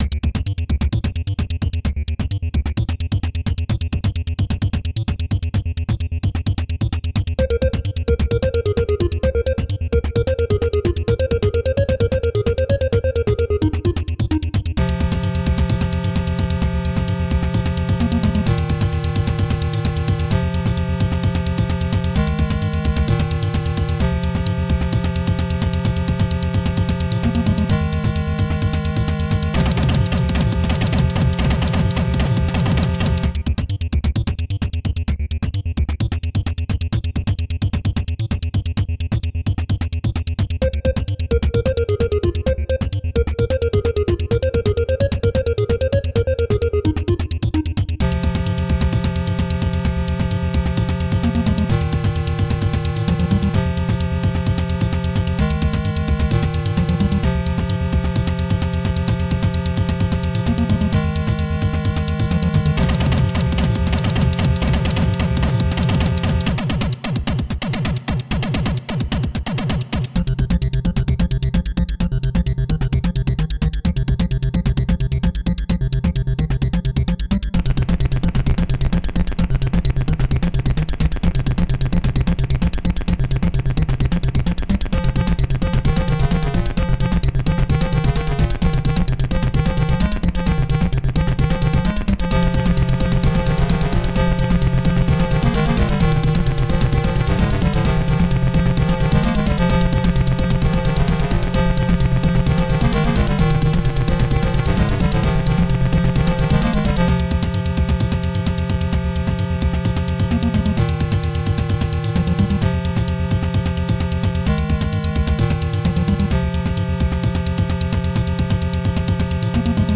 It's another Game Boy-ish song that has nothing to to with chinese people or stock cars except for a few of the chords sounding vaguely asian.